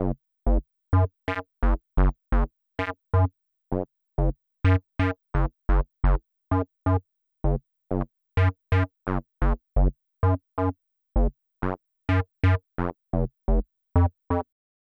TEC Bass Riff Gb-F.wav